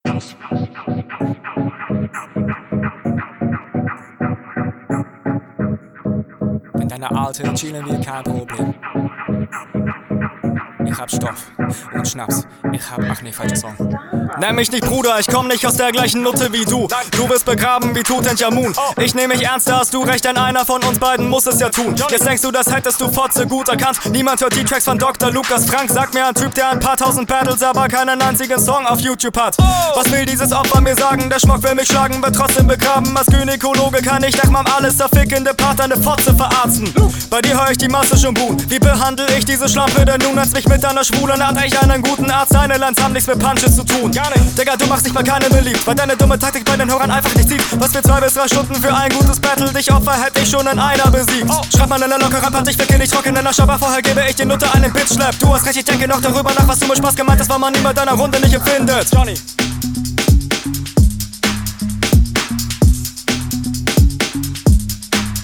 Flow: Ebenfalls stabiler Flow.